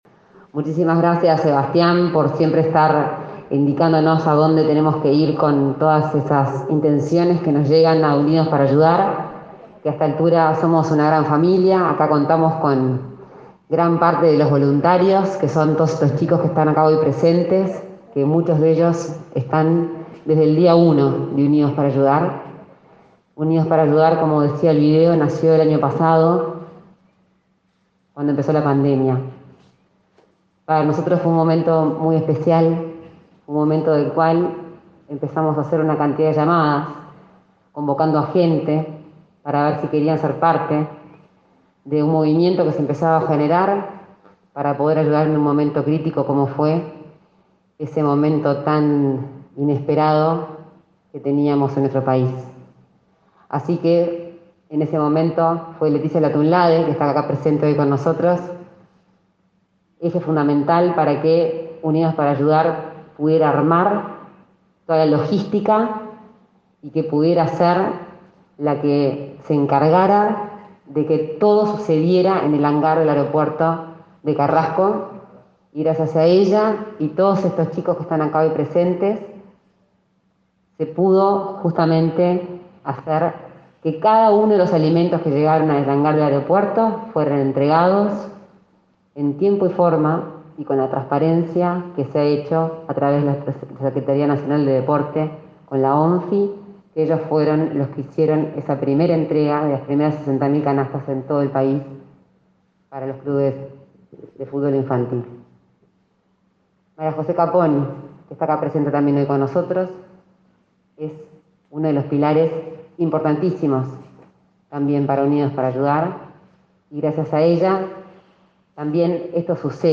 Palabras de Lorena Ponce de León y del subsecretario de Salud, José Luis Satdjian
Palabras de Lorena Ponce de León y del subsecretario de Salud, José Luis Satdjian 28/09/2021 Compartir Facebook X Copiar enlace WhatsApp LinkedIn Este martes 28, la impulsora de Unidos para Ayudar, Lorena Ponce de León, y el subsecretario de Salud Pública, José Luis Satdjian, participaron de la entrega de botiquines para espacios deportivos, mediante la Secretaría Nacional del Deporte.